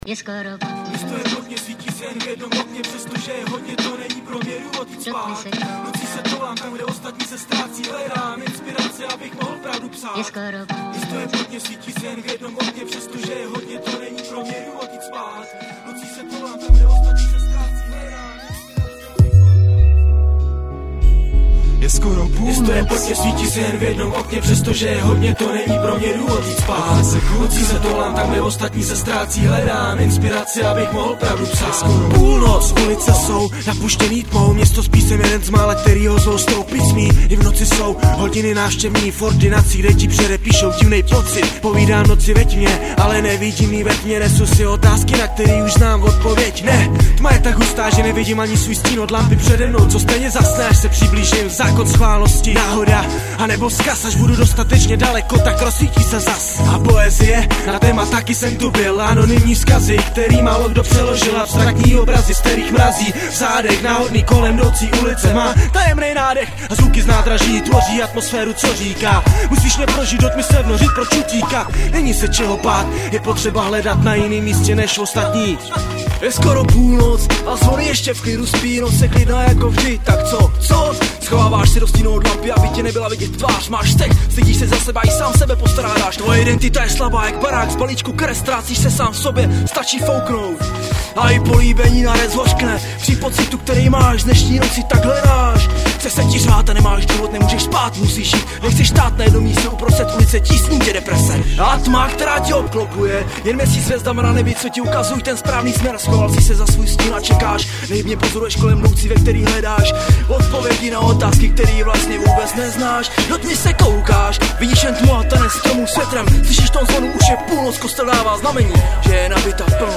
17 Styl: Hip-Hop Rok